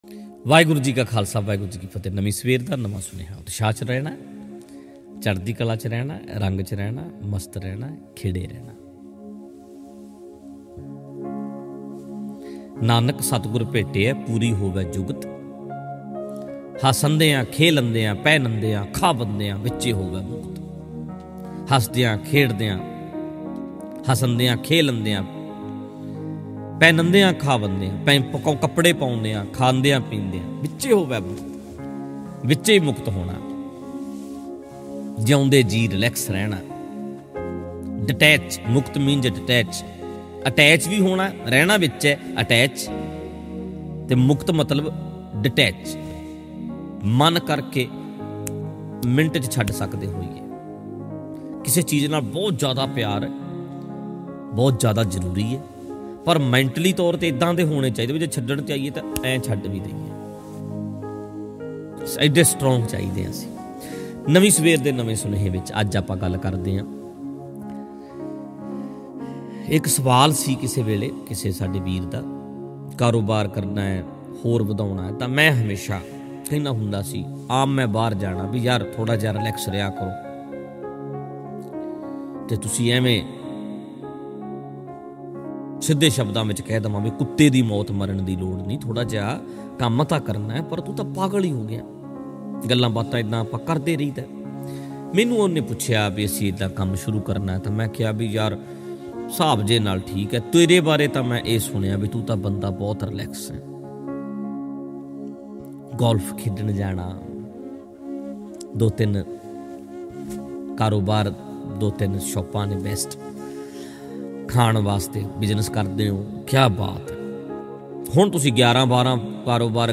Mp3 Diwan Audio by Bhai Ranjit Singh Ji khalsa Dhadrian wale at Parmeshar Dwar